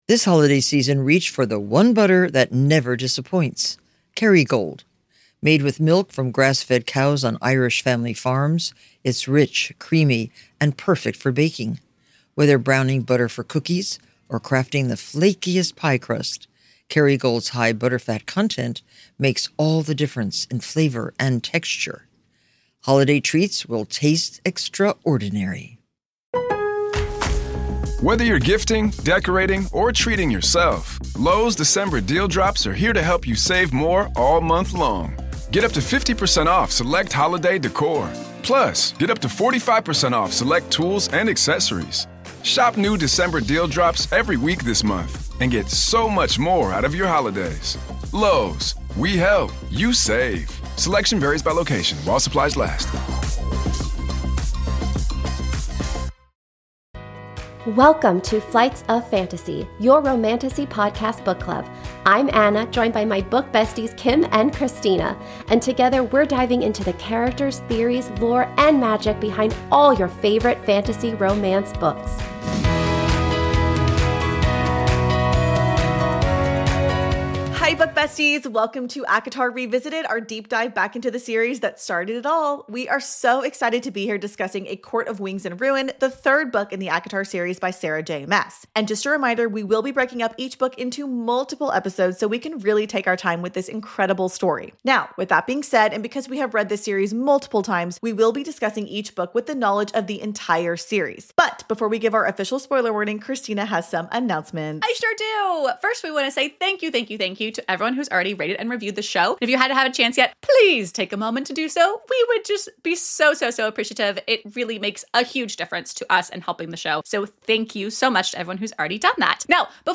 Just a few cauldron blessed and night court obsessed, fire breathing bitch queen besties talking all things fantastical.
new additions to the genre or the genre in general... copious amounts of wine and laughter included.